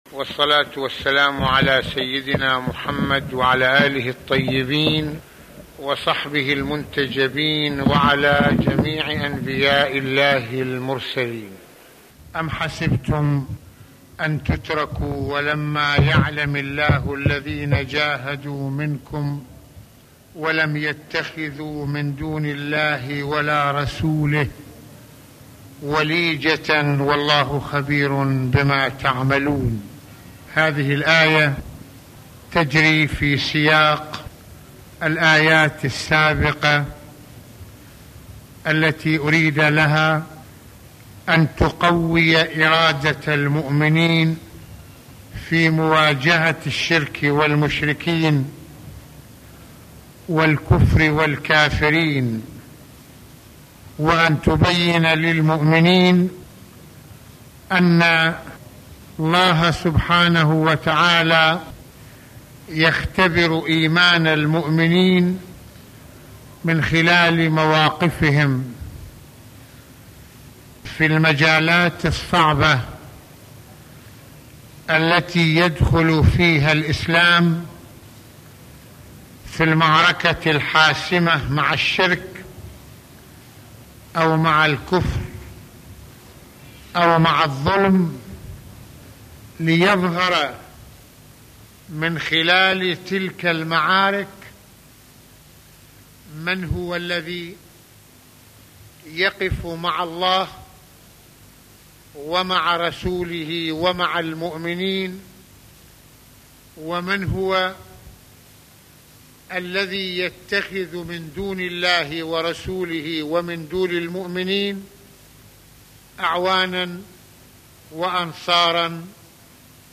- يتحدث العلامة المرجع السيد محمد حسين فضل الله (رض) في هذه المحاضرة القرآنية عن معركة الإيمان والكفر ويتعرض لمسألة إعمار المساجد بالبعد المعنوي المتمثل بالدعوة بإخلاص إلى الله تعالى وليس للمشركين حظٌ في الإعمار ما داموا كافرين بالله ويسيرون في نقيض التوحيد مضيفا الكلام حول أهمية المسجد ودوره ومكانته في الإسلام على الصعد كافة وصفات إنسان المسجد في انفتاحه على الله والكون وتوحيده إياه رغم كل التحديات والمخاطر ...